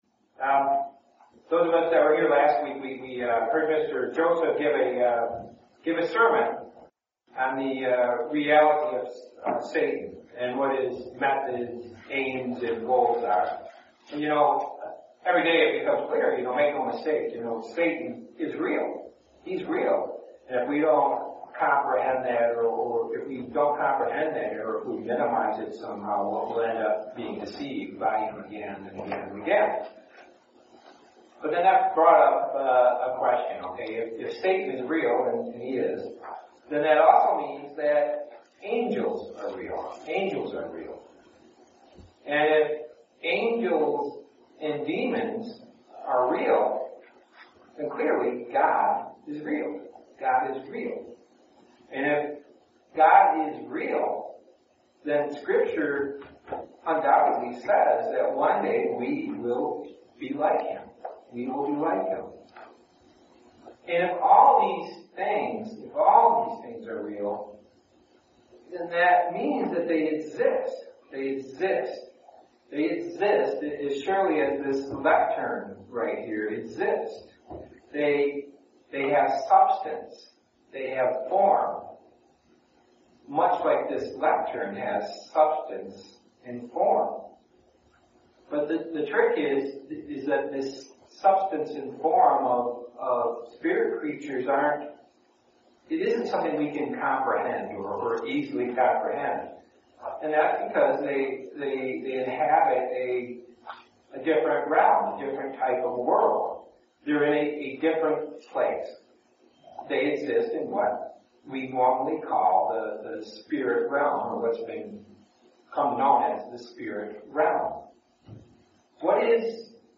In this sermon we'll explore some of the attributes of spirit bodies as outlined in the bible.
Given in Grand Rapids, MI